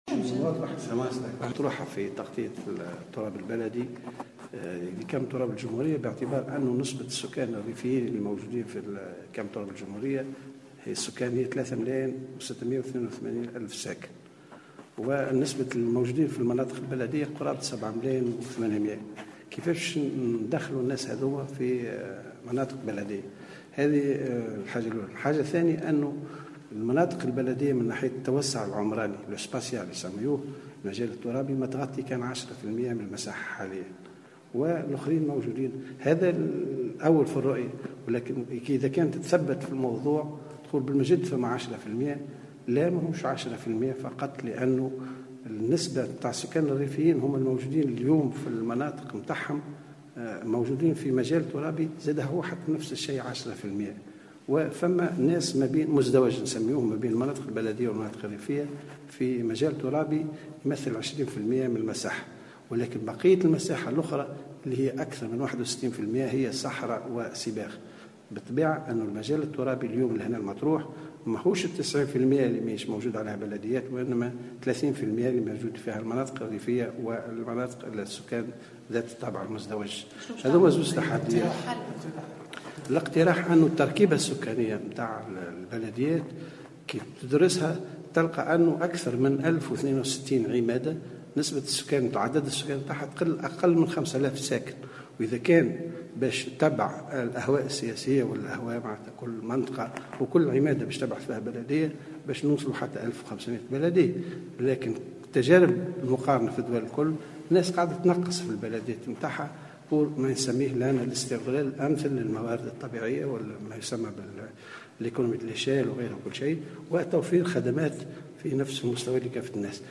أكد مدير عام الجماعات المحلية بوزارة الداخلية، مختار الهمامي، في تصريح لمراسل الجوهرة أف أم اليوم الثلاثاء، أن نسبة السكان الريفيين الموجودين في كامل تراب الجمهورية يبلغ 3.682 مليون ساكن ، مضيفا أن الرهان الذي تواجهه الدولة يتمثل في كيفية إلحاقهم بالمناطق البلدية.